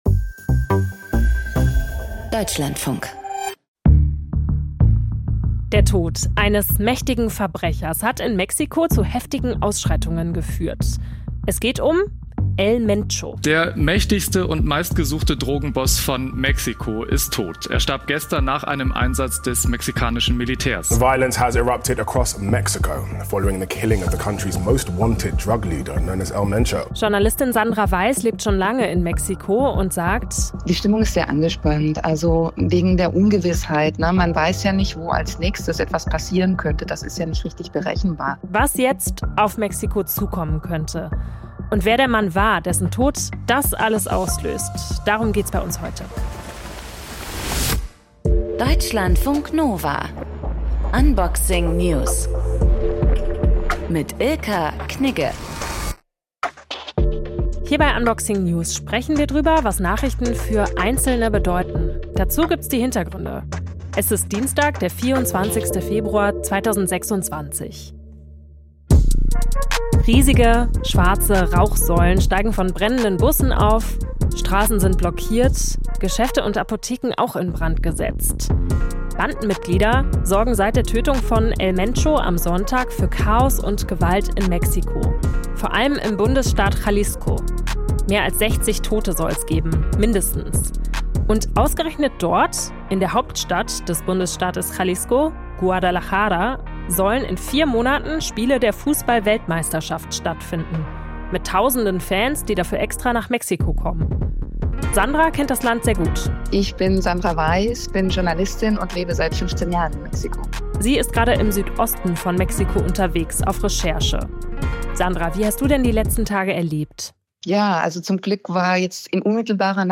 Gesprächspartnerin